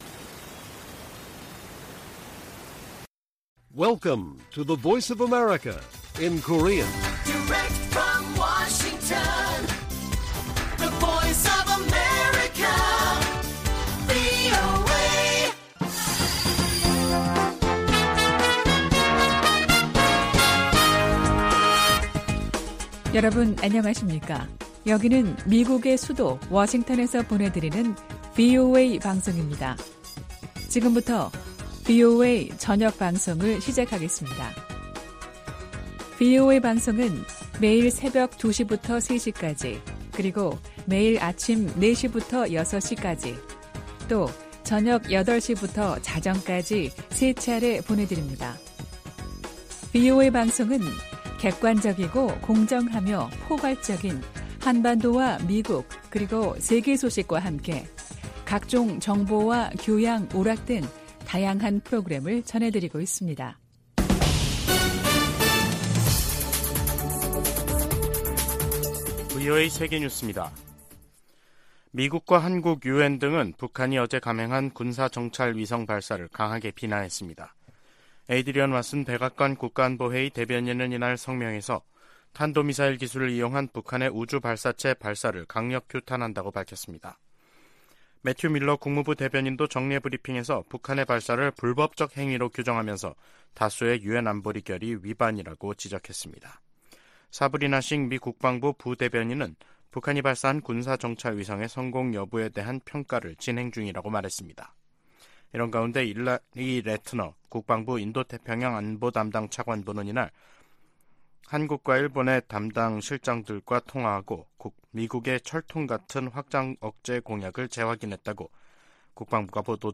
VOA 한국어 간판 뉴스 프로그램 '뉴스 투데이', 2023년 11월 22일 1부 방송입니다. 북한이 군사정찰위성 발사 궤도 진입 성공을 발표하자 미국은 강하게 규탄하고 동맹 방어에 필요한 모든 조치를 취하겠다고 밝혔습니다. 한국 정부는 9.19 남북 군사합의 일부 효력을 정지시켰습니다.